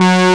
2600 LD   .1.wav